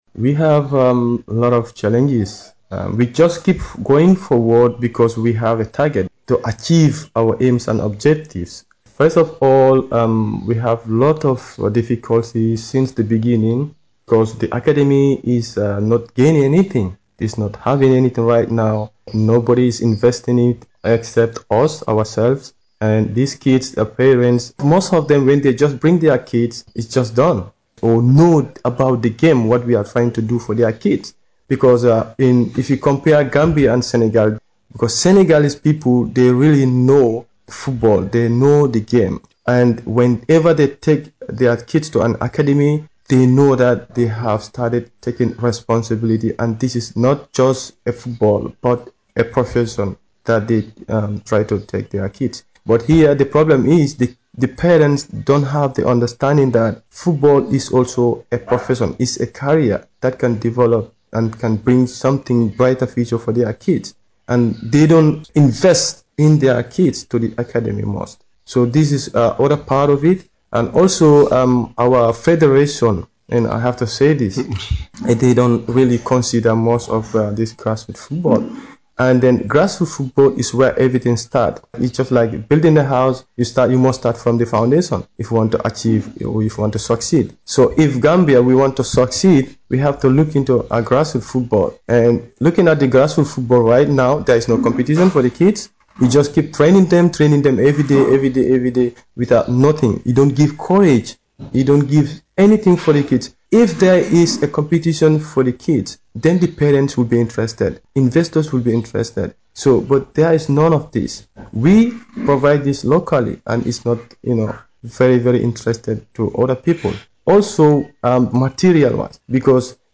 Westcoast interview